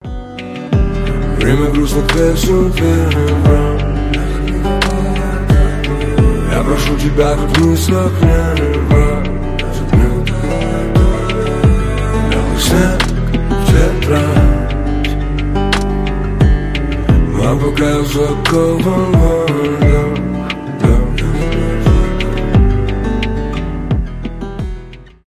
ограбление, тревога, сигнализация, undefined